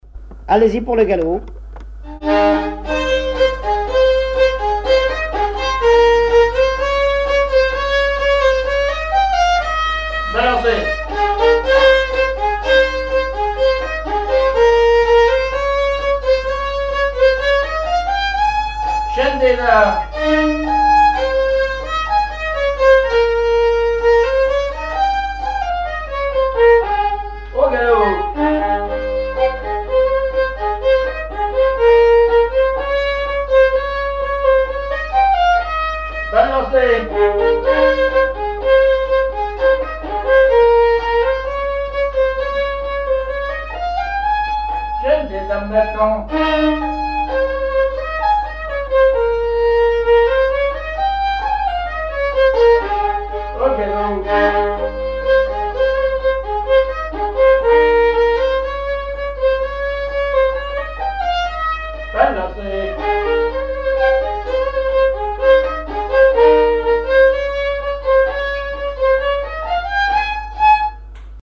Mémoires et Patrimoines vivants - RaddO est une base de données d'archives iconographiques et sonores.
danse : quadrille : grand galop
recherche de répertoire de violon
Pièce musicale inédite